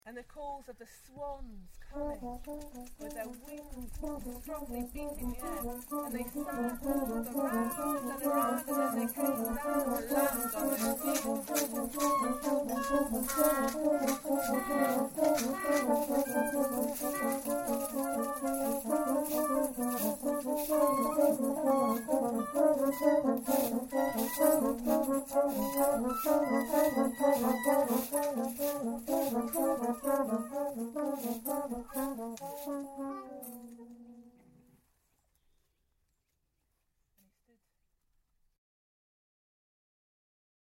performance of swan piece